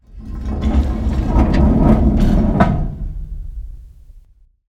Index of /nodejsapp/foundryvtt/public/sounds/doors/metal/
heavy-sliding-open.ogg